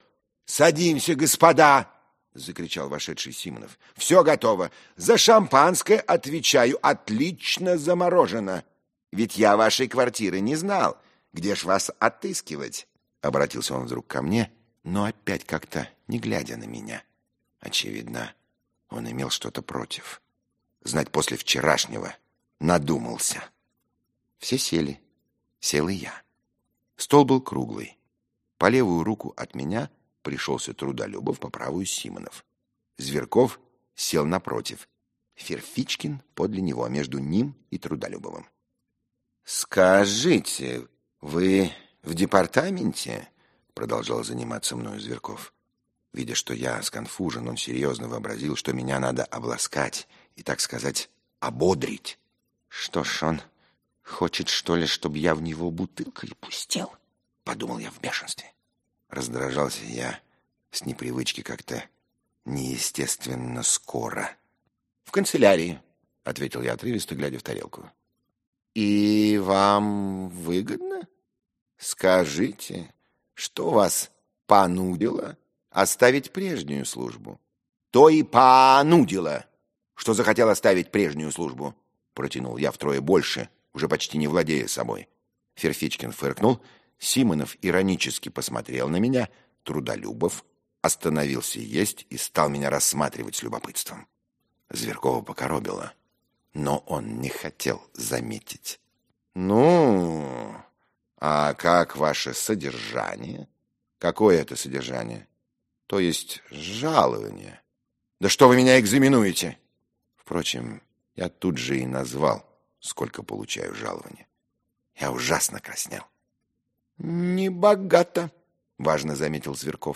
Аудиокнига Записки из подполья | Библиотека аудиокниг
Aудиокнига Записки из подполья Автор Федор Достоевский Читает аудиокнигу Михаил Горевой.